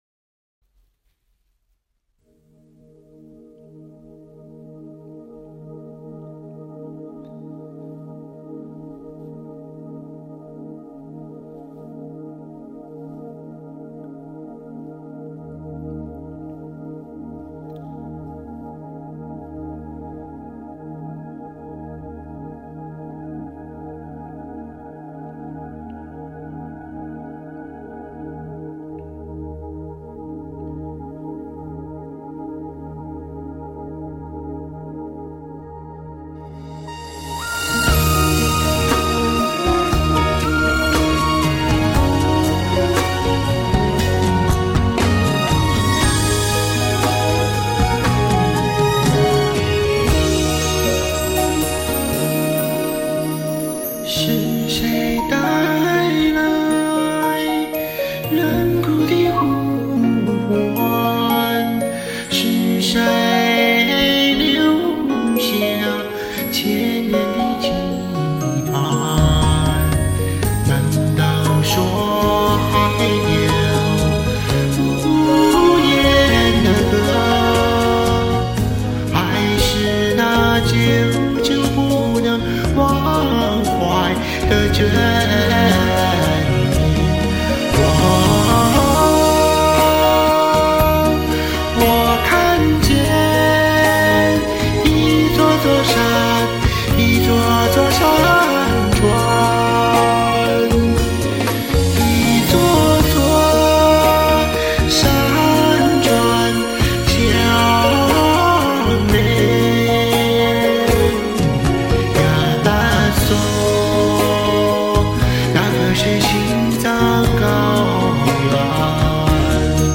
• 好听，确实有点电音的效果～～新春快乐！
是用了电音，要不唱不出真假音切换的感觉。
lol~~深情辽阔宽广，唱得很棒！